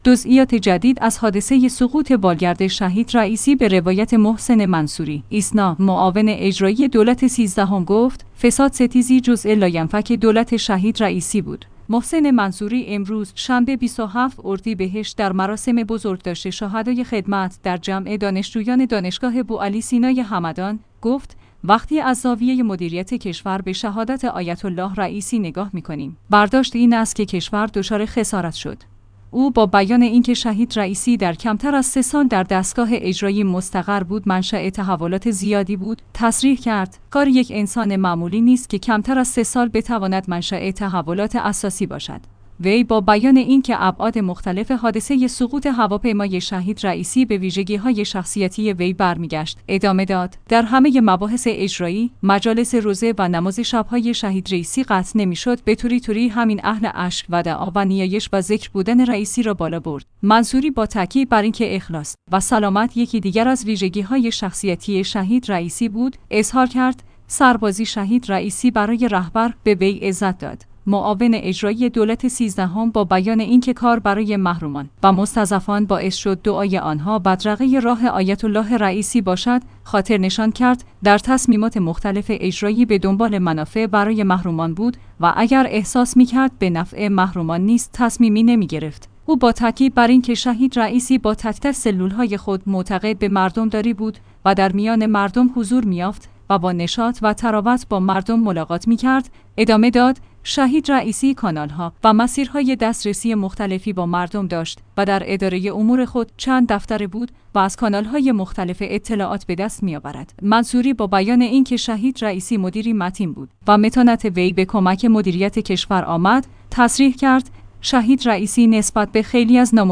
محسن منصوری امروز شنبه ۲۷ اردیبهشت در مراسم بزرگداشت شهدای خدمت در جمع دانشجویان دانشگاه بوعلی سینای همدان، گفت: وقتی از زاویه مدیریت کشور به شهادت آیت‌الله رئیسی نگاه می‌کنیم، برداشت این است که کشور دچار خسارت شد.